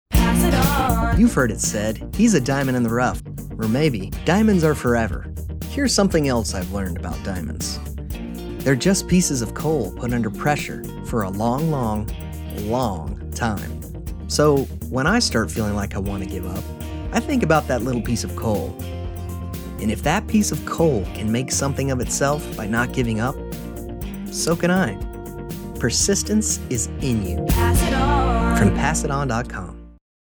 We’re excited to share our new PSA Radio spots that will inspire and motivate.